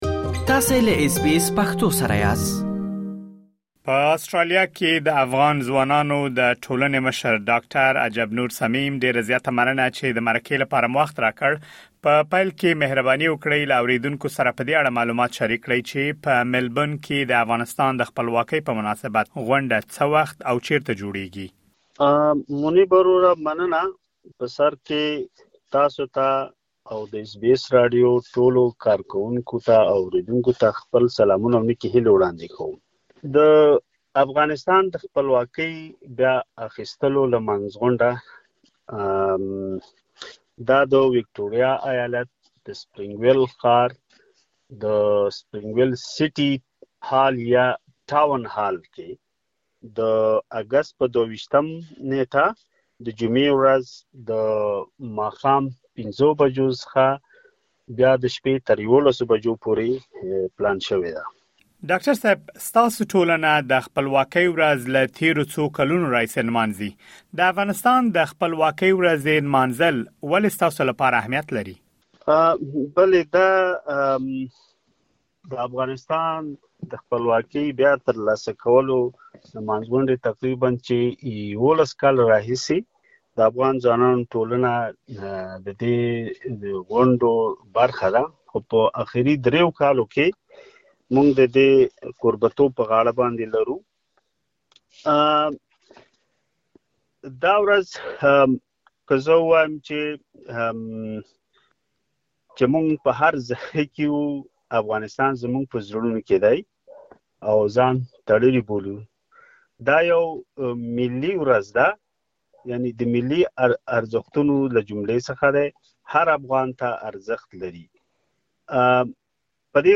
د آسټرالیا په سېډني، ملبورن او کنبرا ښارونو کې مېشت افغانان په پام کې لري ترڅو د افغانستان د خپلواکۍ ۱۰۶مه کالیزه ونمانځي. اس بي اس پښتو د دغو غونډو له تنظیمونکو سره مرکې ترسره کړي.